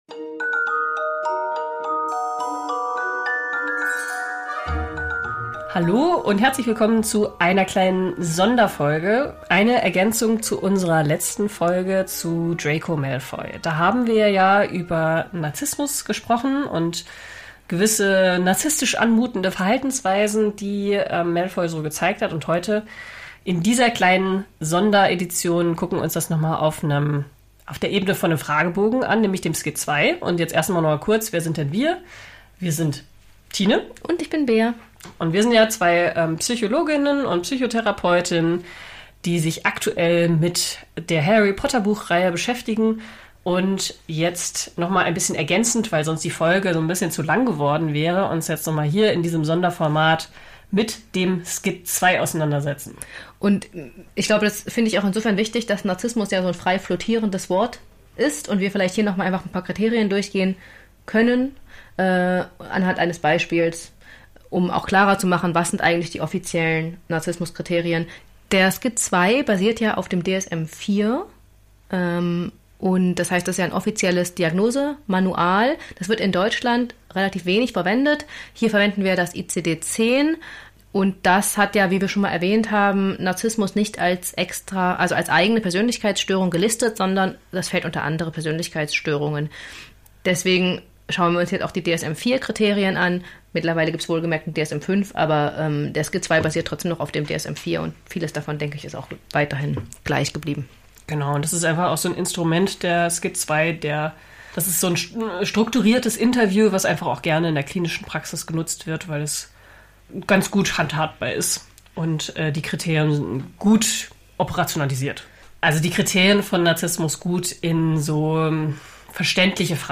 (Items 73–88) ------------------ Wir sind beide ausgebildete Psychotherapeutinnen und unsere Analysen basieren u.a. auf psychologischen Theorien, aber wir erheben keinen Anspruch auf Wahrhaftigkeit und sie ersetzen keine Beratung geschweige denn eine Psychotherapie.